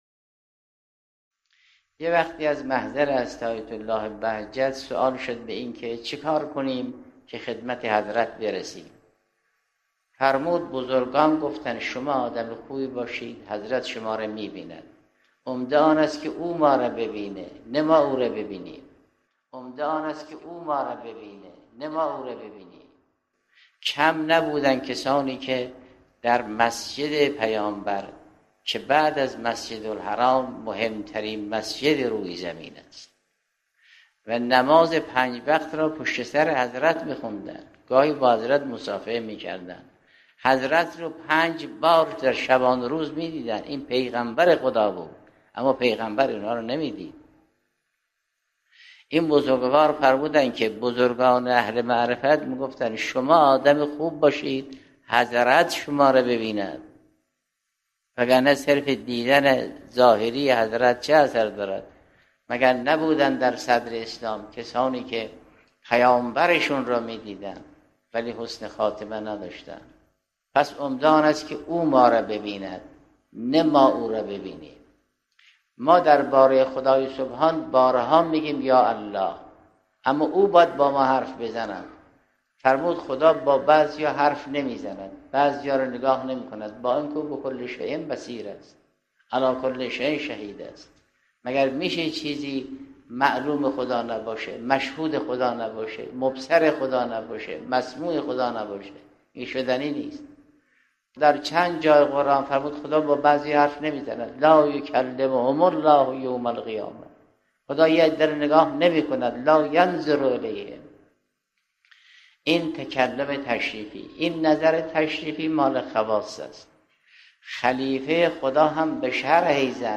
به گزارش خبرگزاری حوزه، حضرت آیت الله جوادی آملی در یکی از دروس اخلاق خود به «راه رسیدن به خدمت امام زمان علیه السلام» اشاره کرده‌اند که تقدیم شما فرهیختگان می شود.